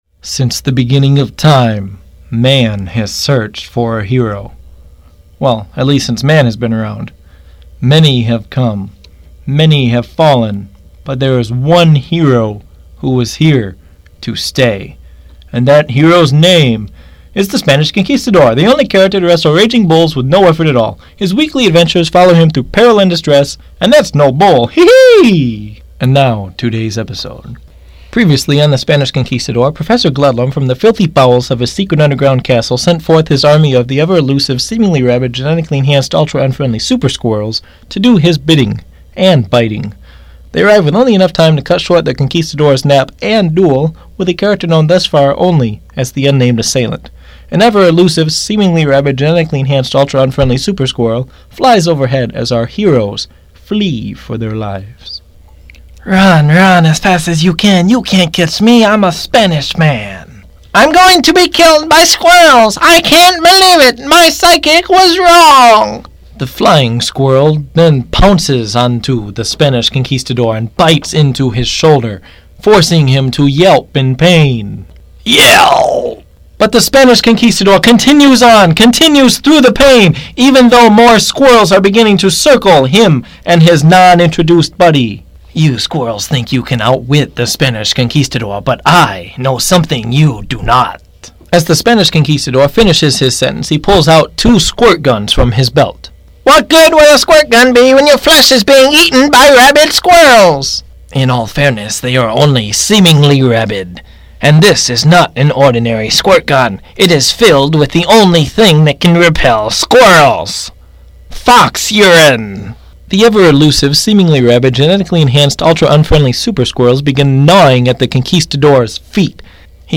The Spanish Conquistador is an ongoing audio comedy series presented by Wayward Orange.